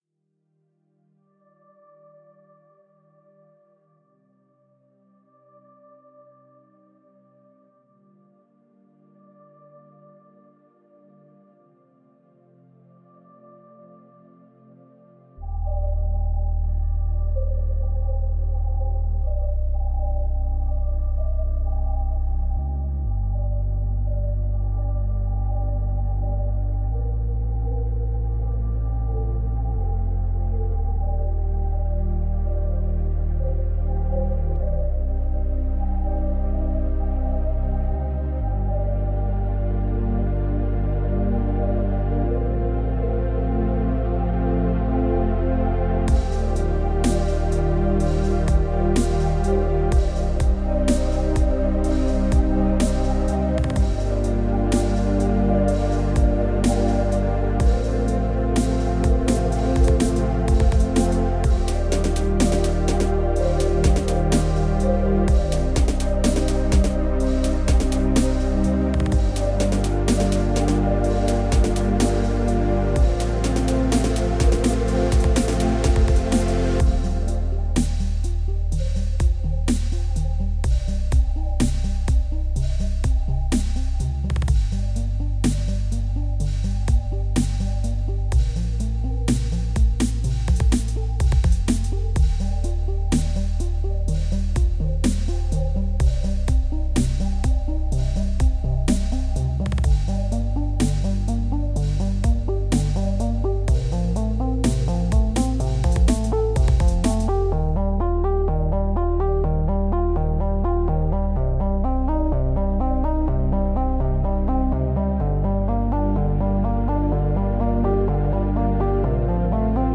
I changed some things up, removed elements which I found distracting, and made minor changes in the melody. Moreover, I added a new arpeggiated Synthesizer.
Also I did not mention it earlier, but bass plays a big role in a song too, so I paid close attention to that as well.